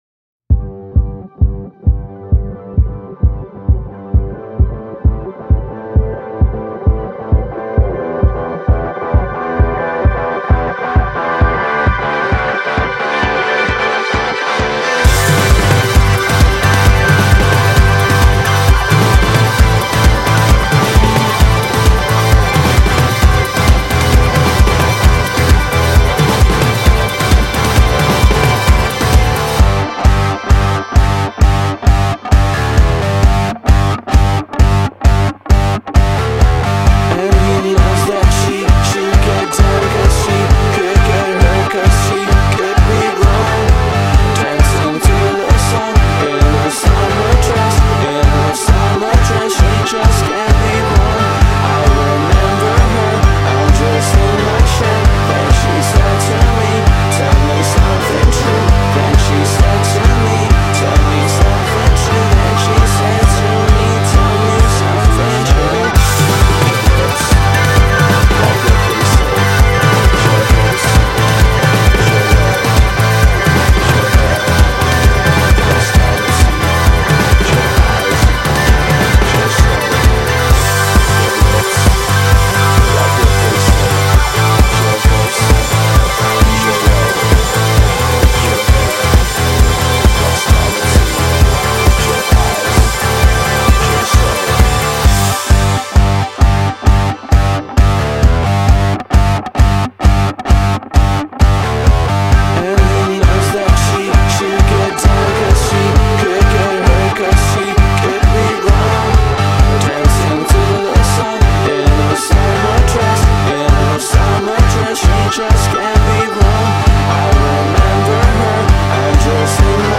Žánr: Pop
Chytlavé melodie, snové synth plochy a výrazná rytmika